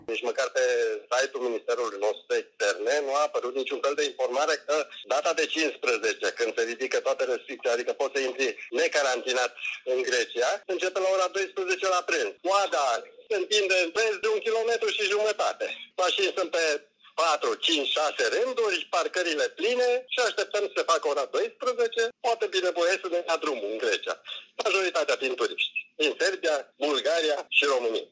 Un șofer a povestit pentru Europa FM că s-au format cozi la granița dintre Bulgaria și Grecia.